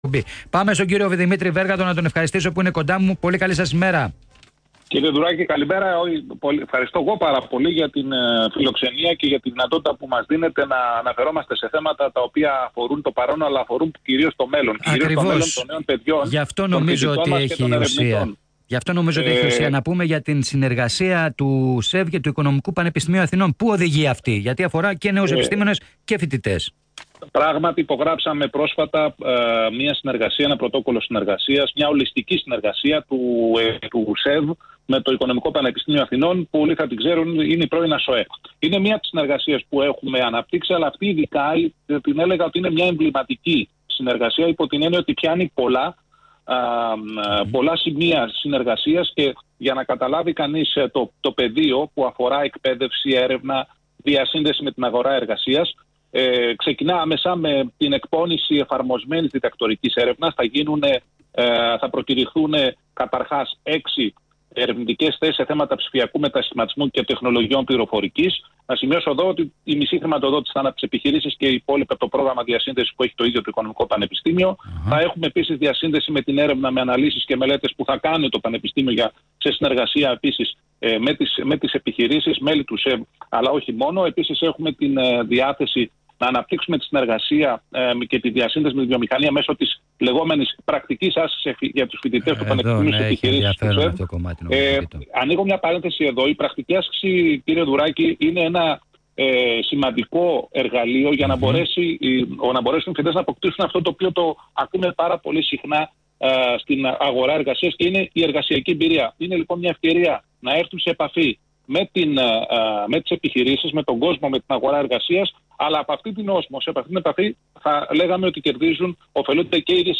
Συνέντευξη
στον Ρ/Σ ALPHA 989